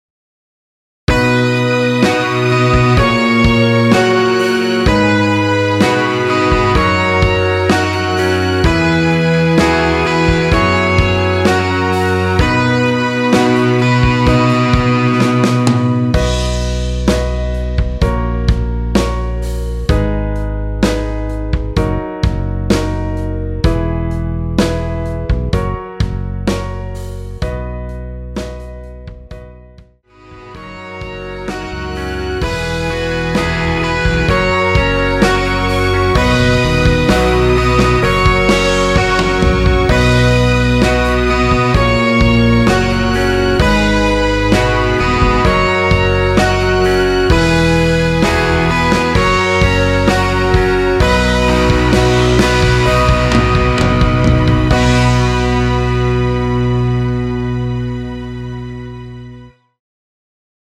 원키에서(+4)올린 MR입니다.
앞부분30초, 뒷부분30초씩 편집해서 올려 드리고 있습니다.